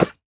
Minecraft Version Minecraft Version 25w18a Latest Release | Latest Snapshot 25w18a / assets / minecraft / sounds / mob / armadillo / hurt_reduced2.ogg Compare With Compare With Latest Release | Latest Snapshot
hurt_reduced2.ogg